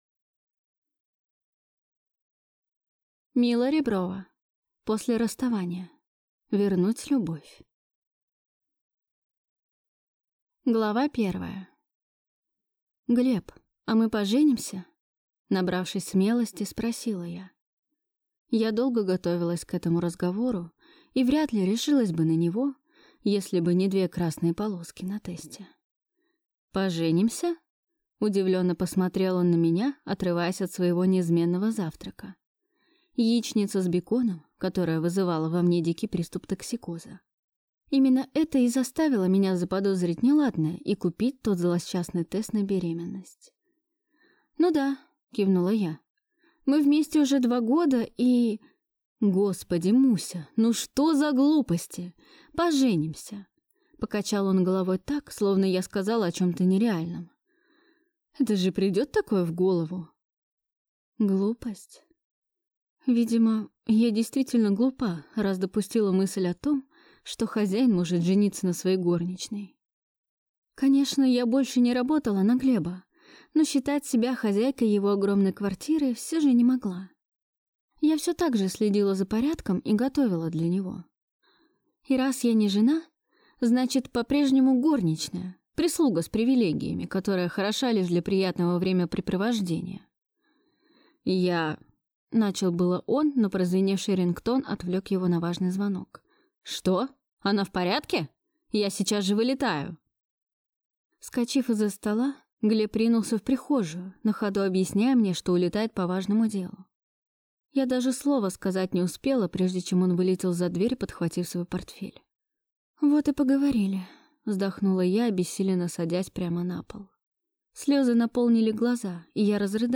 Аудиокнига После расставания. Вернуть Любовь | Библиотека аудиокниг